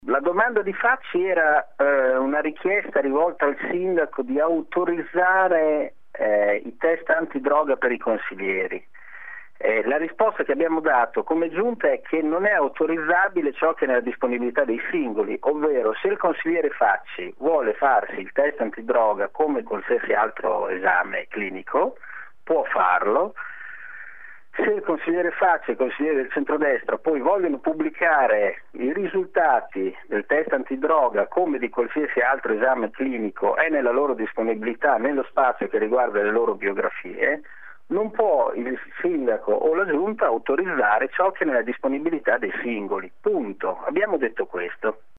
«Se il consigliere vuole farsi il test antidroga può farlo e altrettanto se vuole pubblicarne i risultati», chiarisce Merighi: